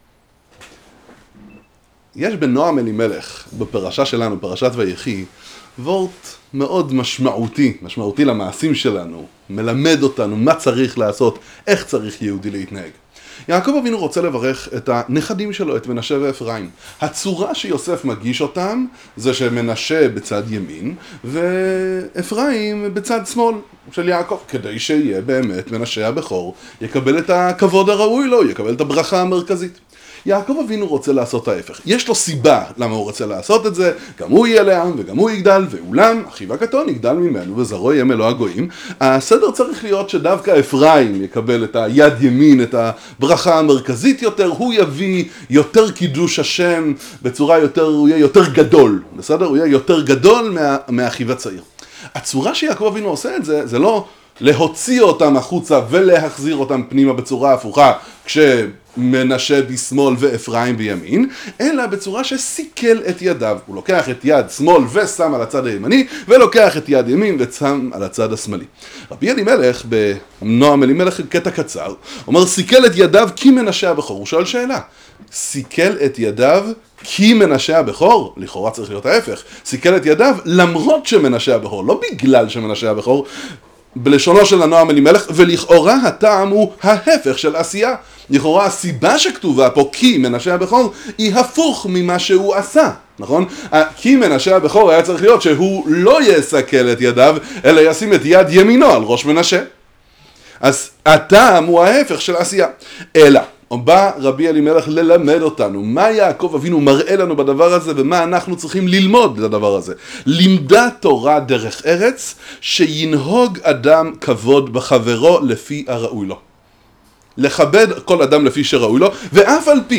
שיעור קצר לכל מורה והורה – חכמת ההנהגה: איך לעשות מה שנכון תוך שמירה על כבוד הזולת? נועם אלימלך ויחי